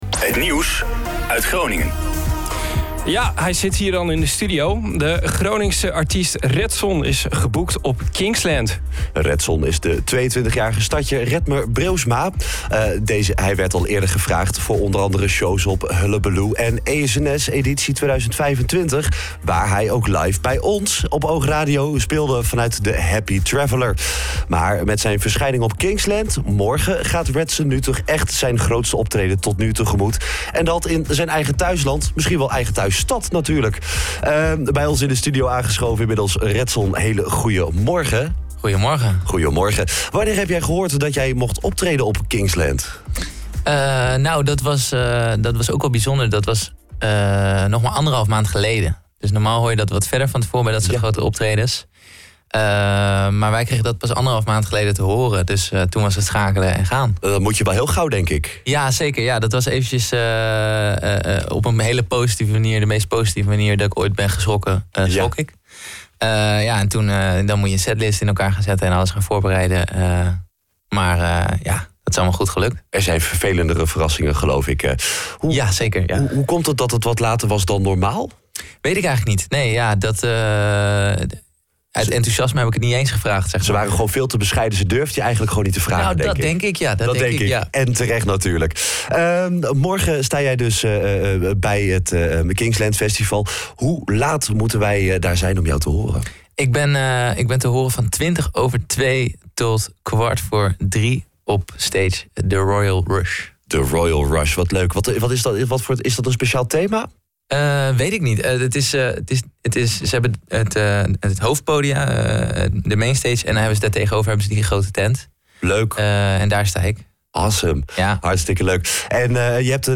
De OOG Ochtendshow is een vrolijke radioshow met het lokale nieuws, de beste muziek en natuurlijk het weer en beluister je iedere werkdag van 07:00 – 09:00 uur op OOG Radio.